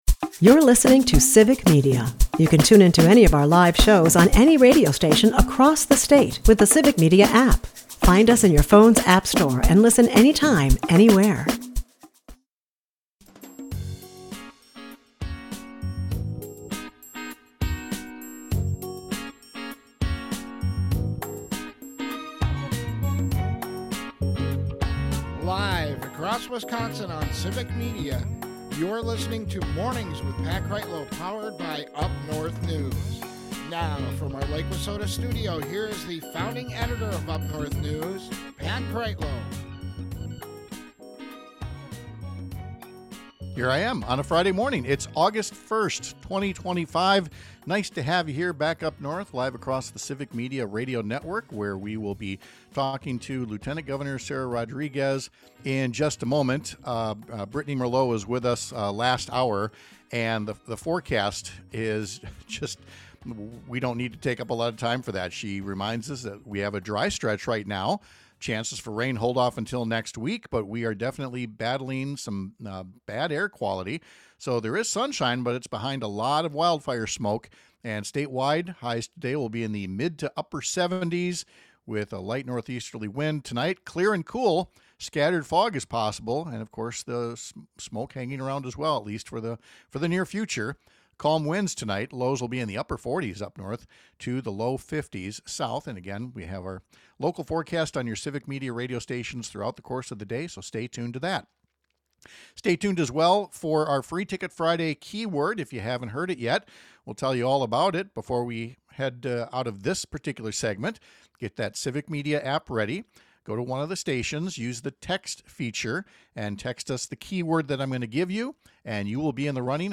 2025 44:02 Listen Share We’re talking live with Lt. Gov. Sara Rodriguez, the first announced candidate in next year’s race for Wisconsin governor. She’ll talk about her background as a nurse and healthcare leader, what prompted her to run for a seat in the Legislature before becoming the state’s second highest executive, and what she wants to do if elected to succeed Tony Evers in November 2026.